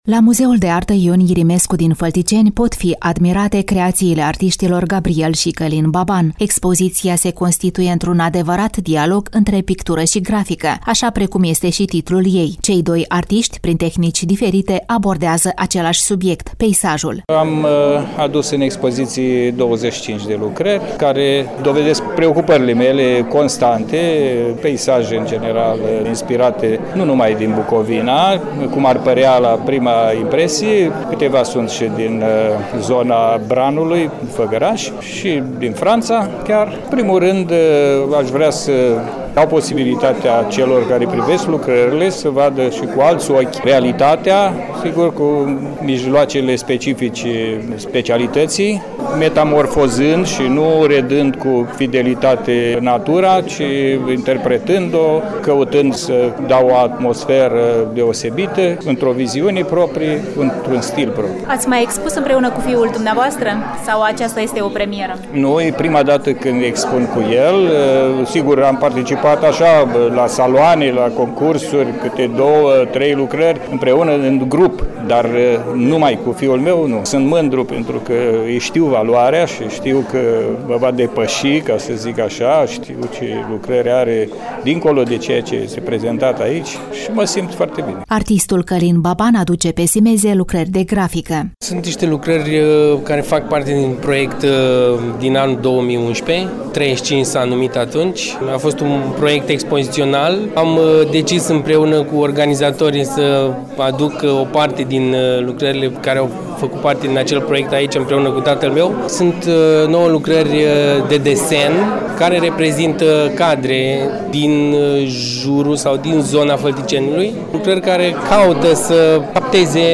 Prima pagină » Rubrici » Reportaj cultural » Expoziţia “Dialog” la Muzeul de Artă “Ion Irimescu” din Fălticeni